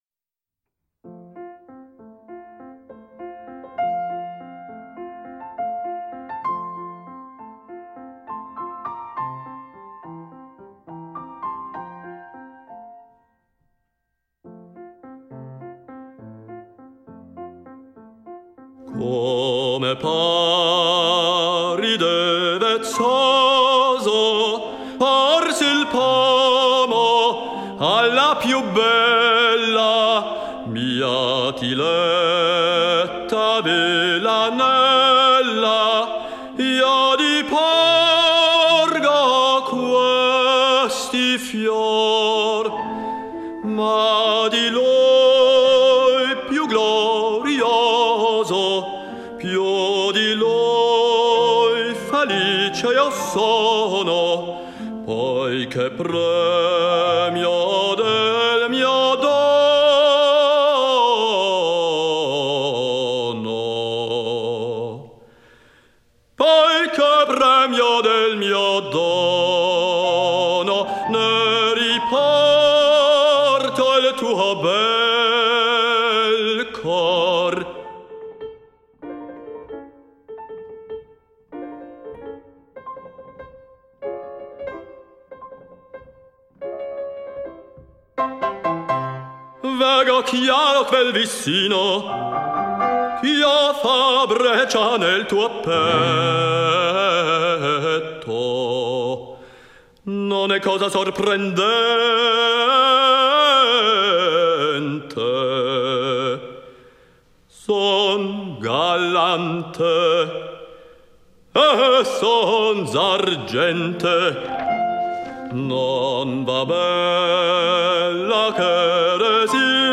Bassbariton